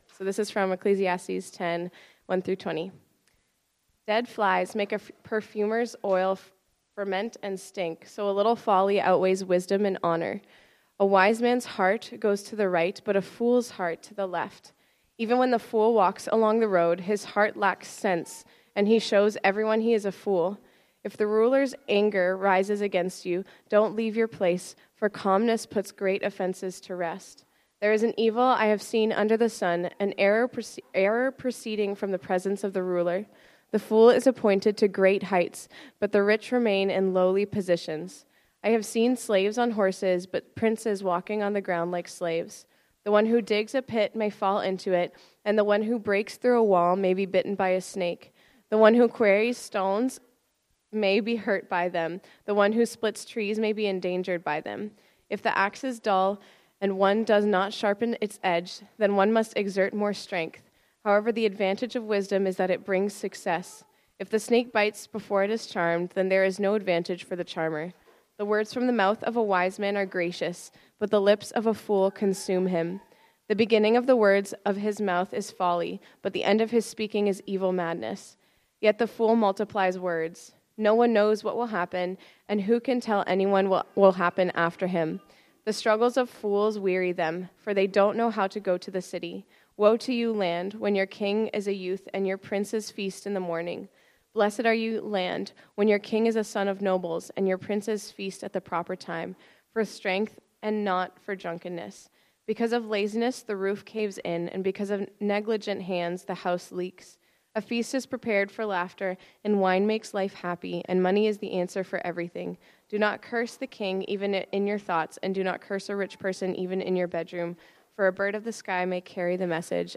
Bible Text: Ecclesiastes 10:1-20 | Preacher